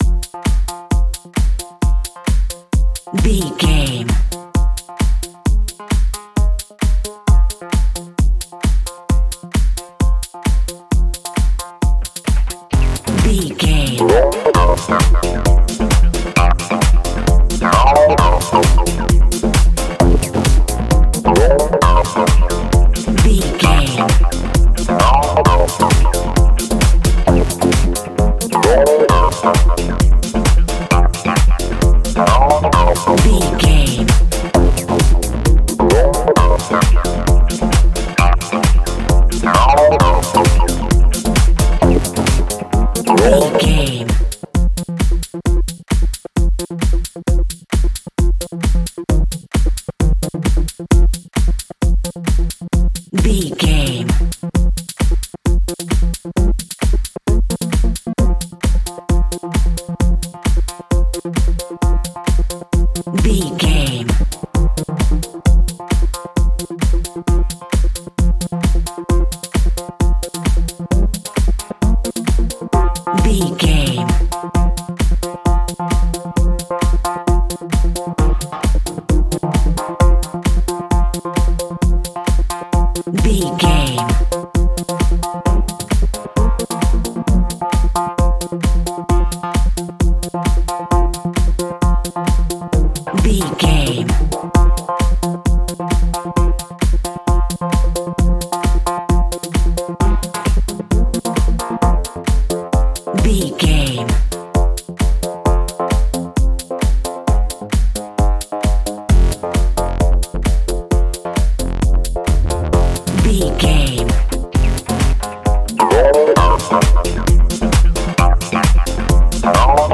Aeolian/Minor
hypnotic
industrial
mechanical
groovy
uplifting
drum machine
synthesiser
electronic
dance
techno
trance
synth leads
synth bass